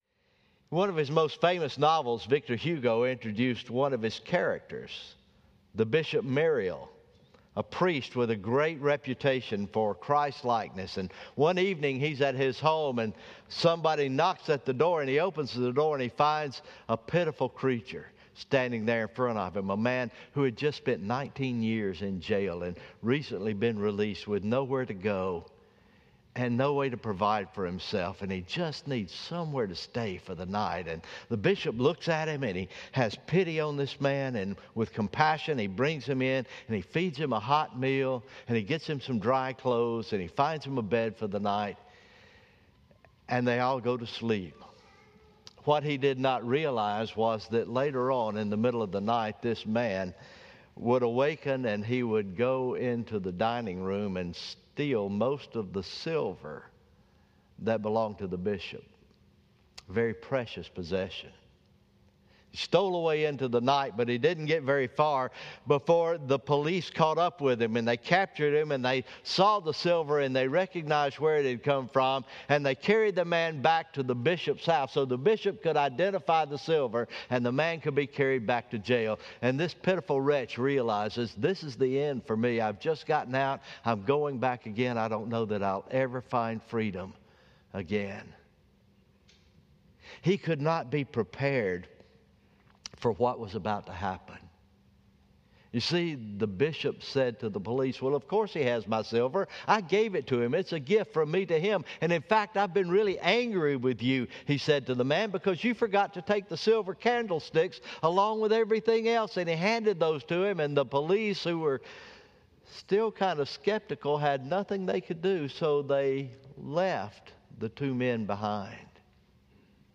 October 18, 2020 Morning Worship